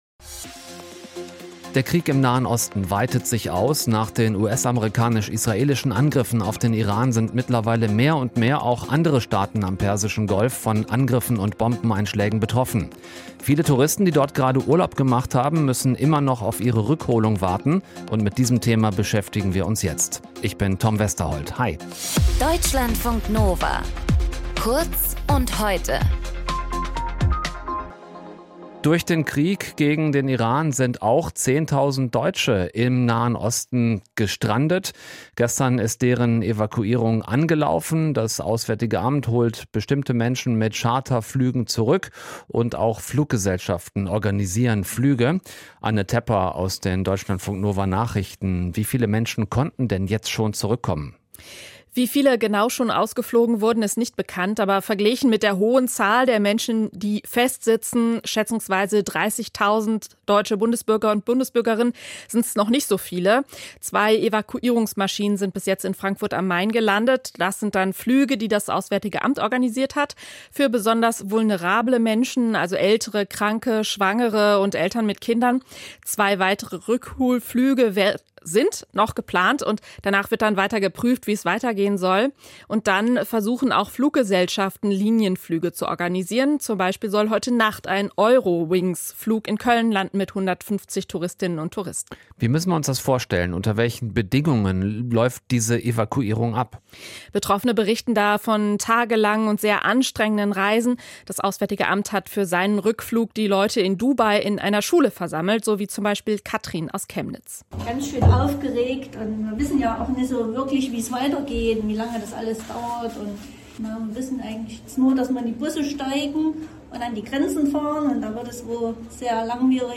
Moderation:
Gesprächspartnerin: